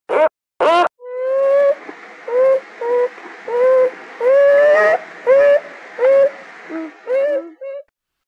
Звуки бобров
Крики бобров в ночи